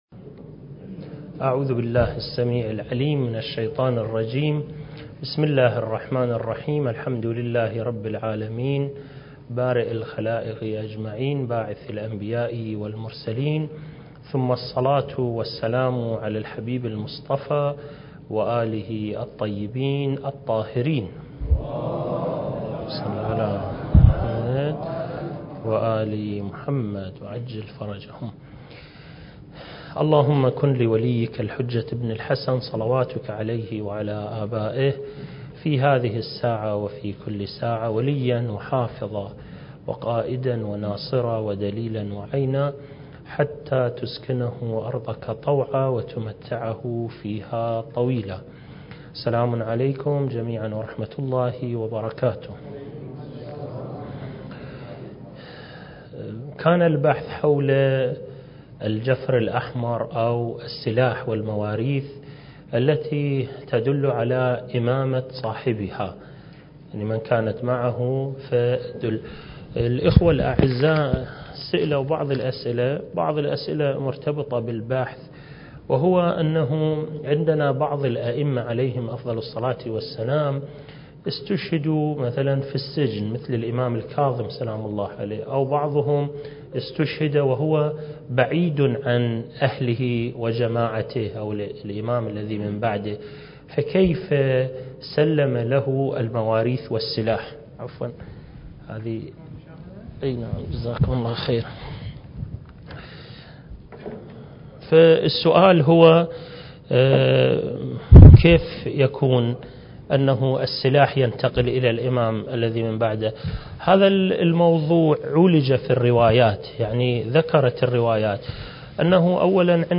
المكان: مؤسسة الإمام الحسن المجتبى (عليه السلام) - النجف الأشرف دورة منهجية في القضايا المهدوية (رد على أدعياء المهدوية) (10) التاريخ: 1443 للهجرة